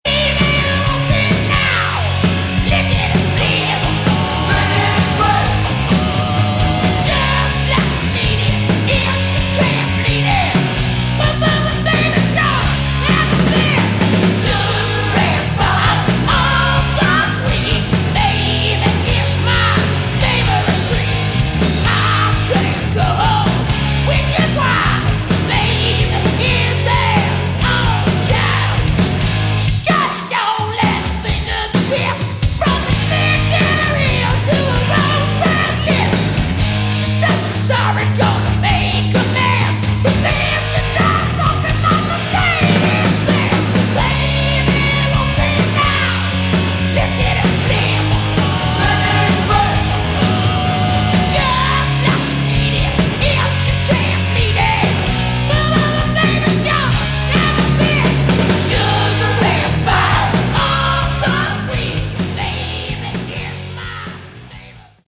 Category: Sleaze Glam / Hard Rock